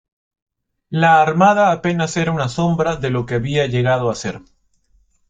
som‧bra
/ˈsombɾa/